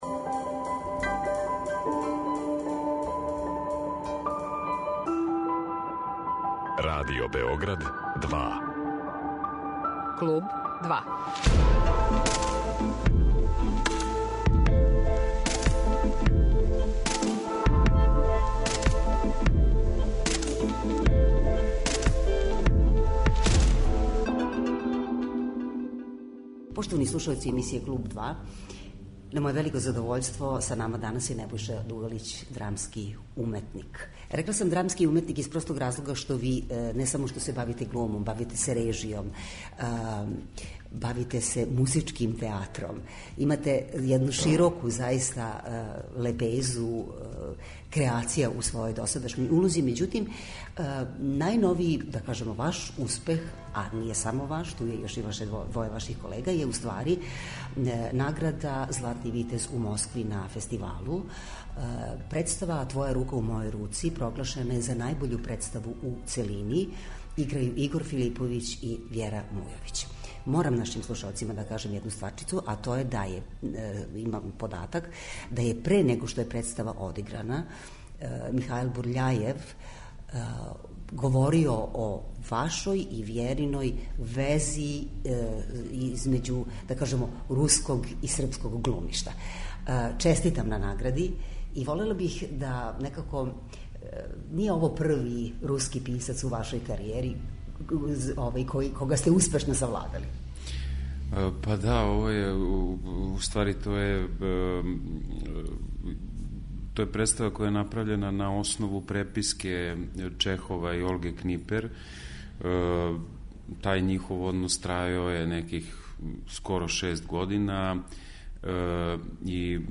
У емисији Клуб 2 чућемо поново разговор са Небојшом Дугалићем, глумцем и редитељем представе "Твоја рука у мојој руци", која jе награђена у Москви наградом "Златни витез".